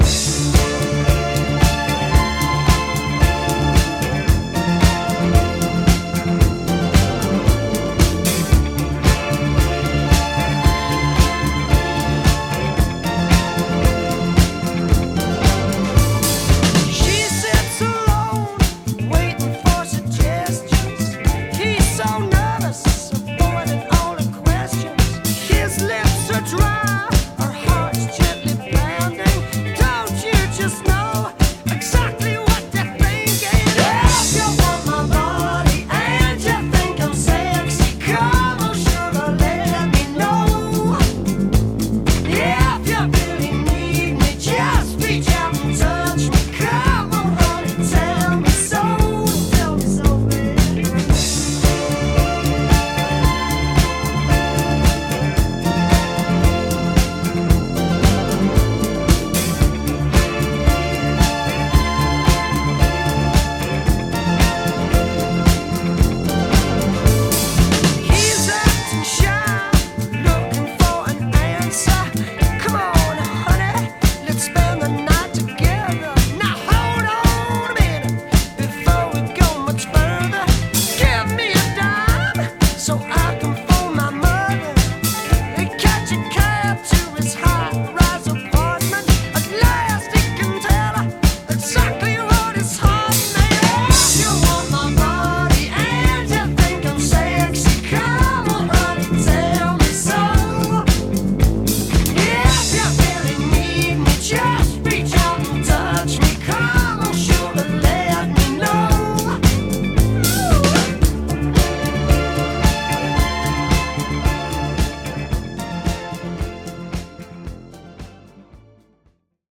BPM110-114
Audio QualityLine Out